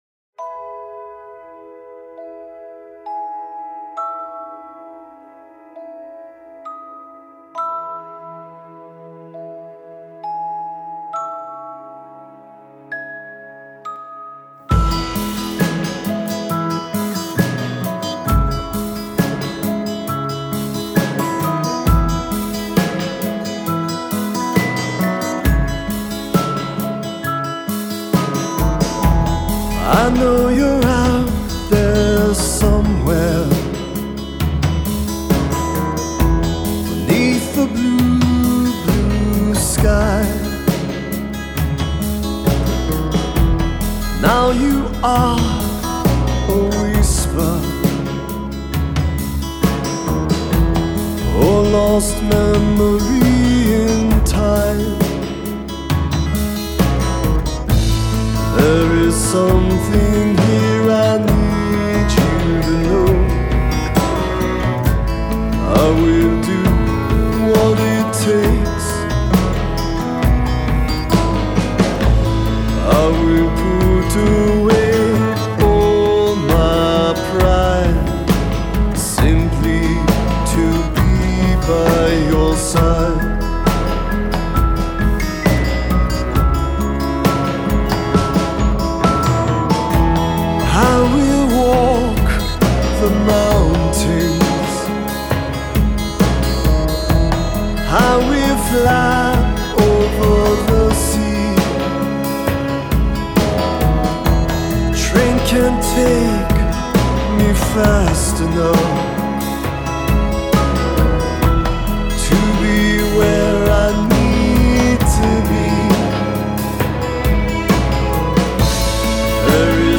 Há uma bela melancolia no trabalho.